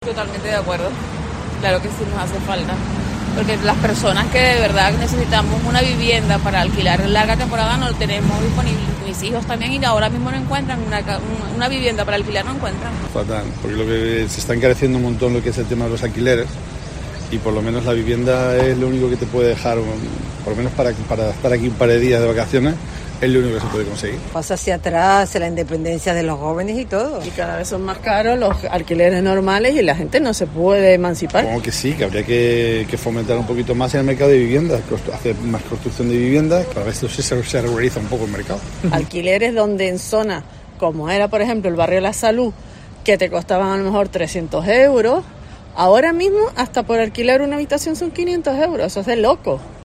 Los canarios opinan en el debate del alquiler vacacional